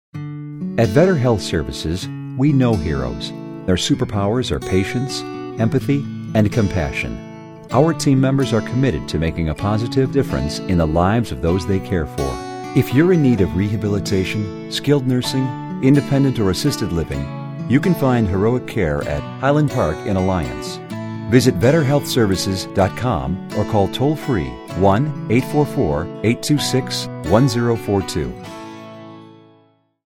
VSL Heroes Radio Spot